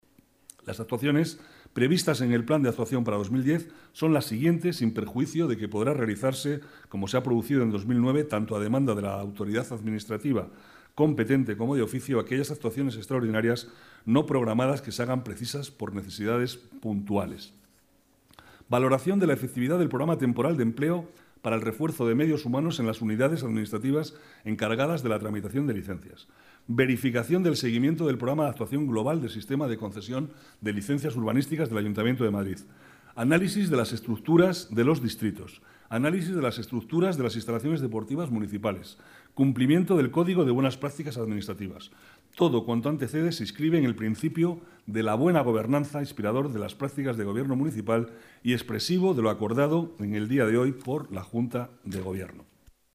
Nueva ventana:Declaraciones de Manuel Cobo, vicealcalde, sobre la inspección general de servicios